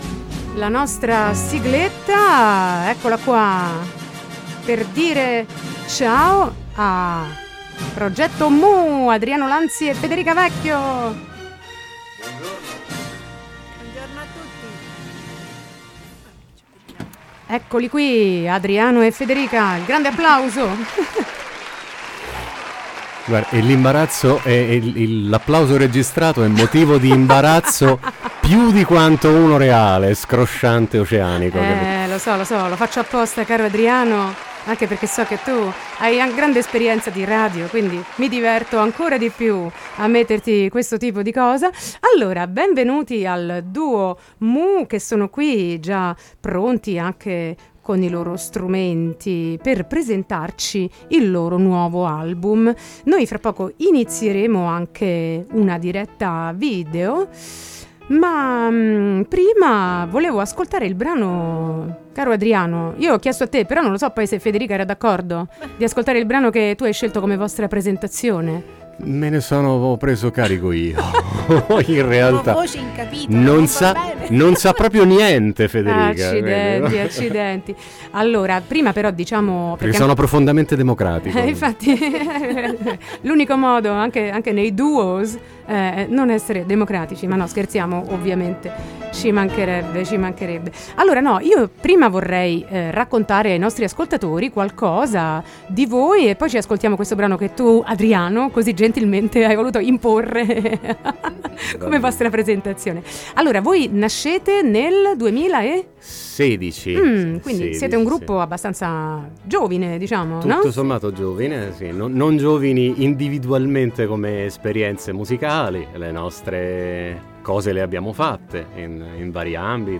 con un minilive in studio